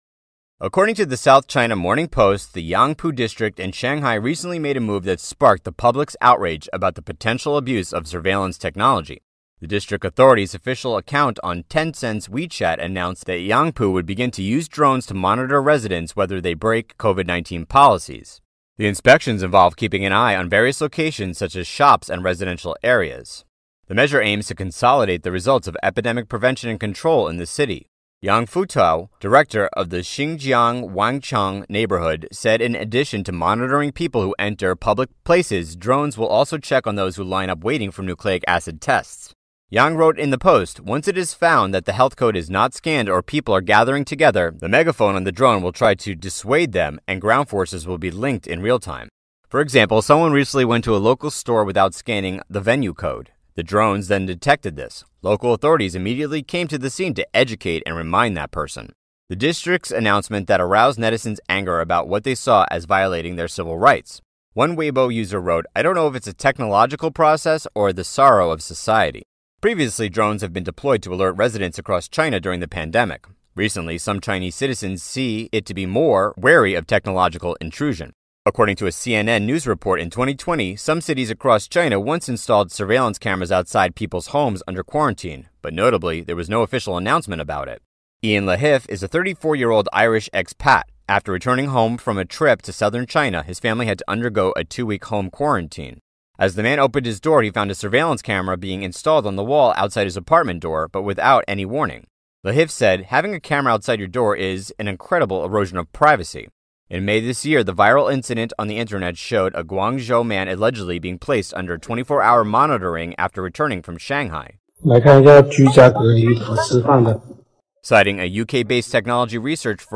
In Shanghais Bezirk Yangpu werden Drohnen eingesetzt, um Einheimische zu überwachen, wenn Covid-Regeln nicht befolgt werden. Durch Lautsprecherdurchsagen wird ihnen unmittelbar mitgeteilt, wenn die Maske nicht richtig sitzt.